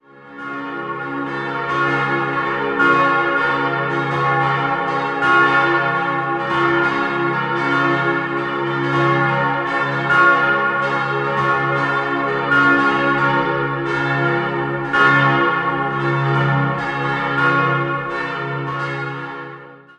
4-stimmiges Salve-Regina-Geläut: des'-f'-as'-b'
St. Ulrich besitzt das tontiefste Geläut der Stadt.